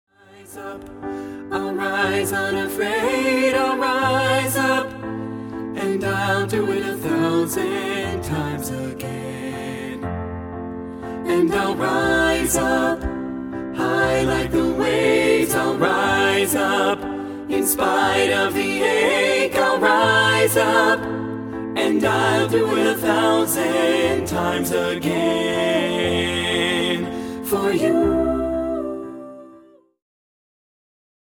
• Full Mix Track